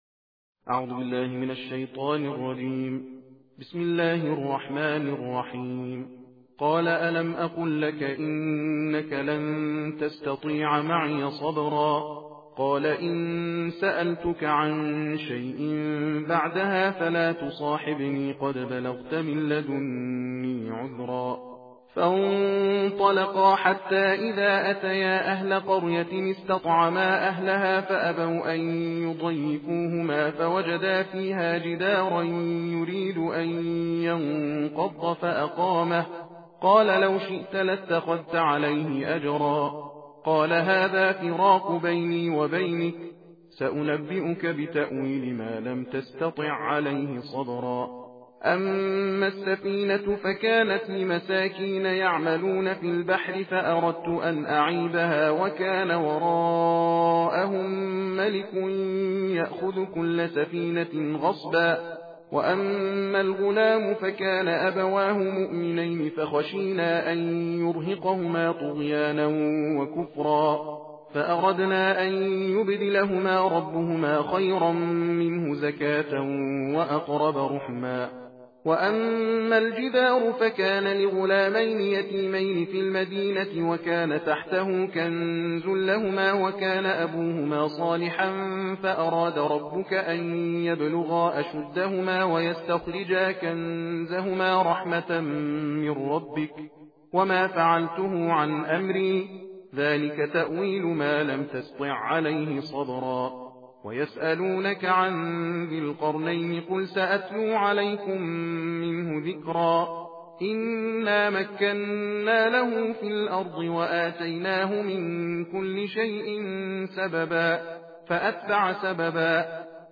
صوت/ تندخوانی جزء شانزدهم قرآن کریم